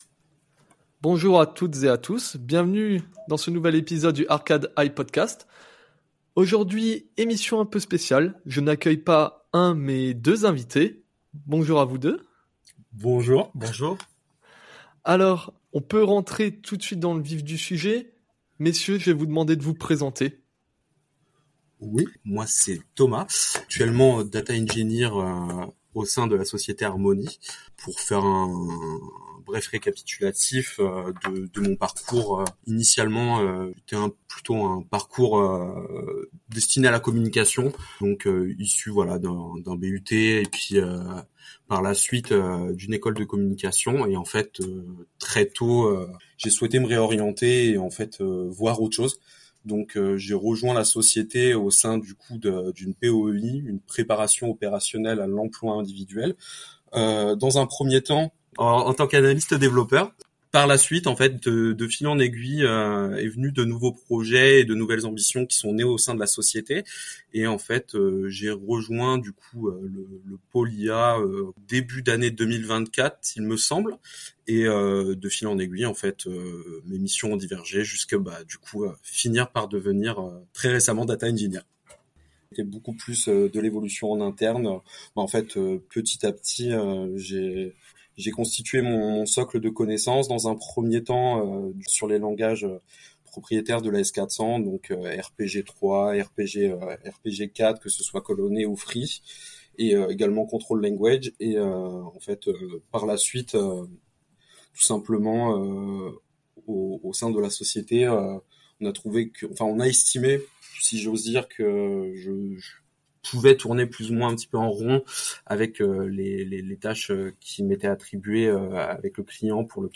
ARCAD i Podcast - Interview